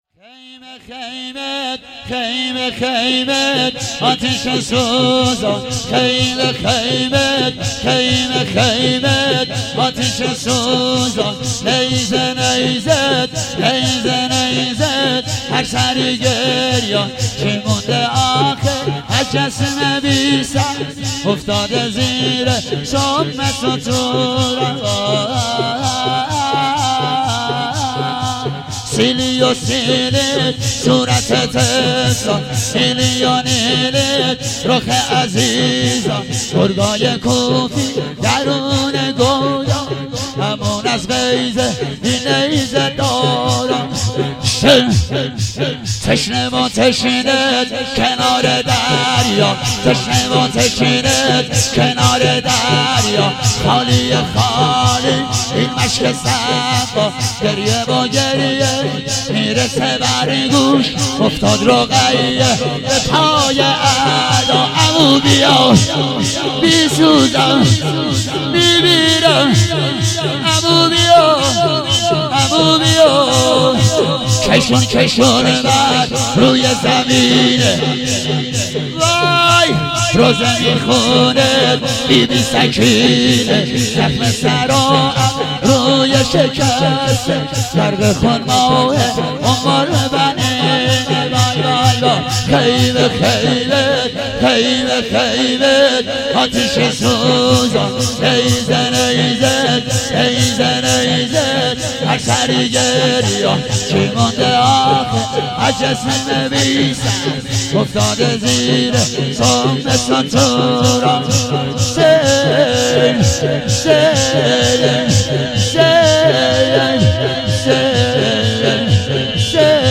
هفتگی 20 دی 97 - شور - خیمه خیمه آتیش سوزان
هفتگی زمستان 97